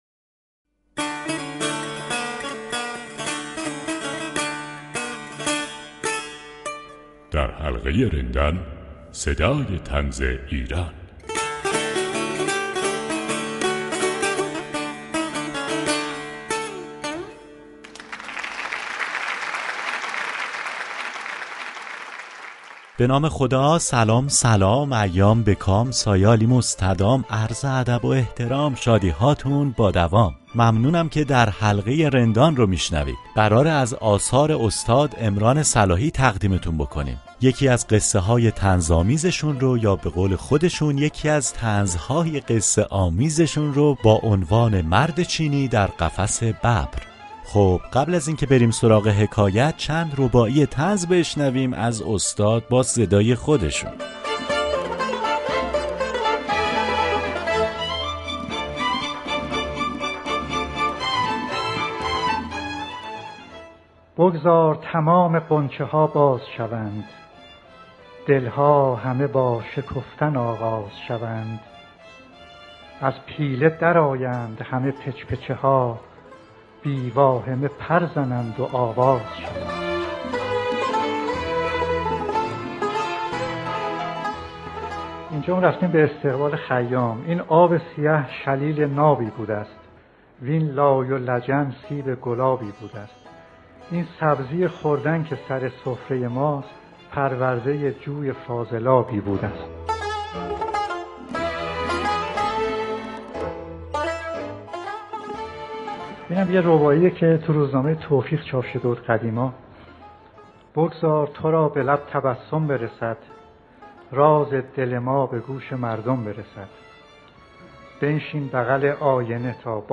در این برنامه چند رباعی طنز با صدای عمران صلاحی می‌شنویم و قصه طنزآمیزی از آثار زنده‌یاد صلاحی با عنوان «من آمده‌ام» تقدیم شما می‌شود.